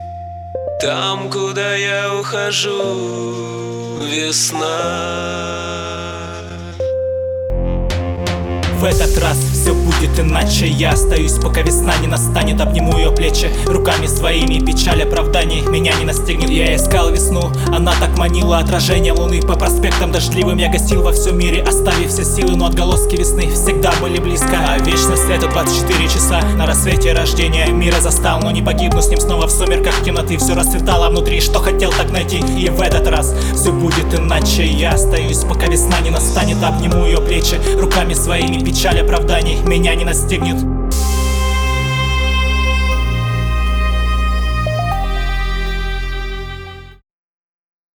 Концептуально, романтично, но исполнение единым монотонным потоком не впечатляет